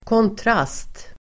Uttal: [kåntr'as:t]